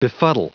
Prononciation du mot befuddle en anglais (fichier audio)
Prononciation du mot : befuddle